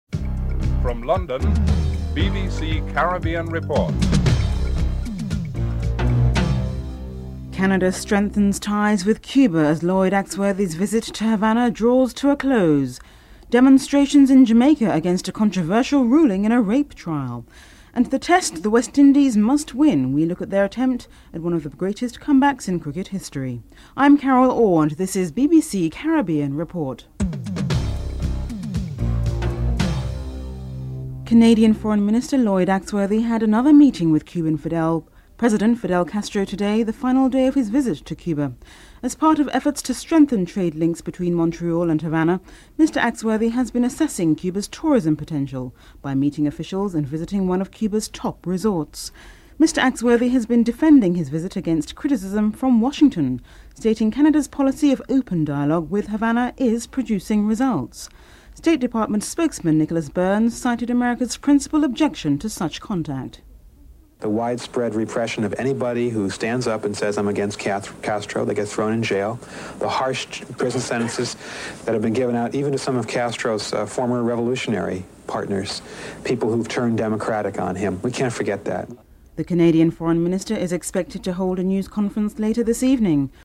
George Briggs, Jamaica's Junior Health Minister is interviewed (05:40-06:54)
Tony Cozier is interviewed (12:34-15:21)